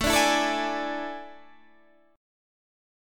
AM7sus4 chord